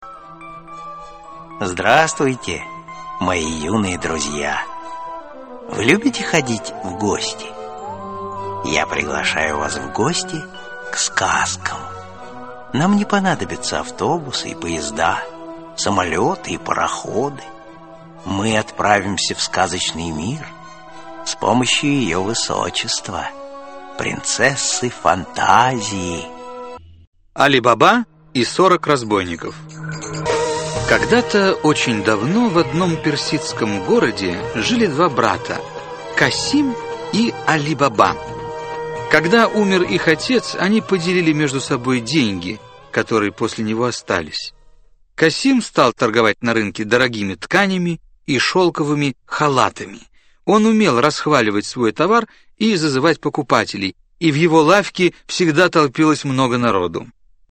Аудиокнига 1000 и 1 ночь | Библиотека аудиокниг